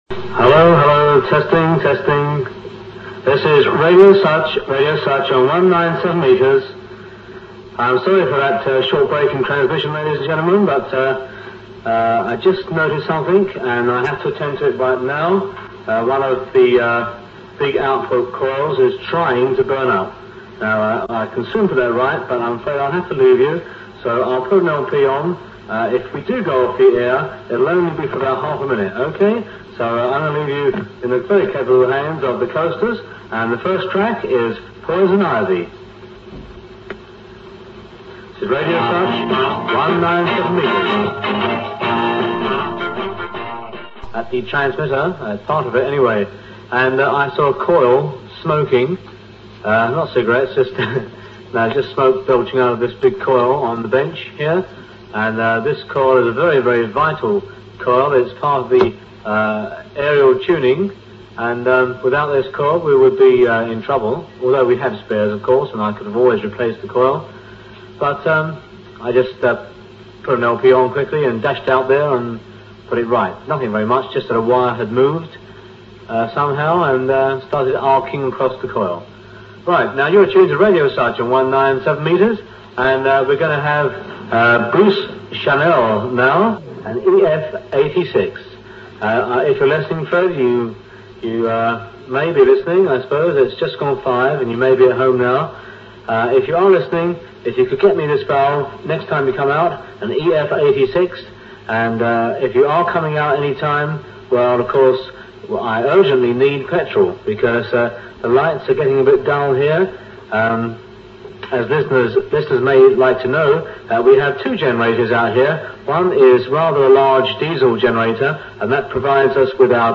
All in a day's work on Radio Sutch!